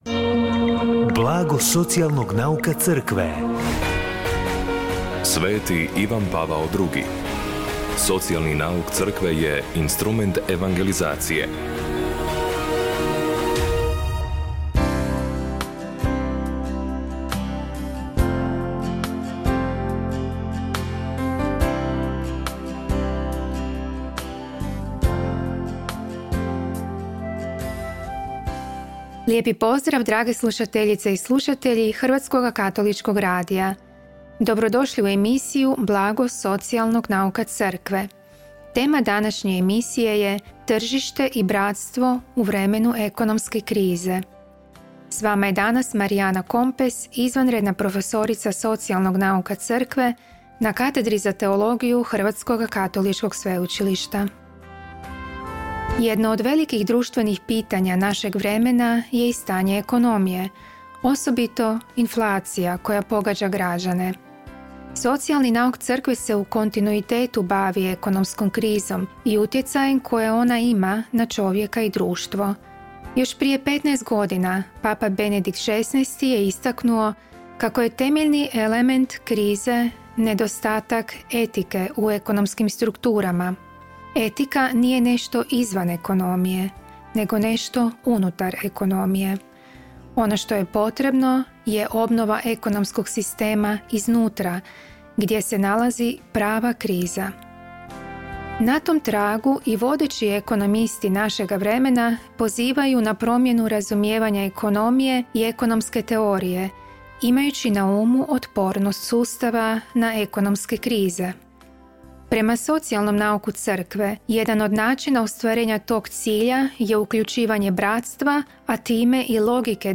Emisiju na valovima HKR-a “Blago socijalnog nauka Crkve” subotom u 16:30 emitiramo u suradnji s Centrom za promicanje socijalnog nauka Crkve Hrvatske biskupske konferencije.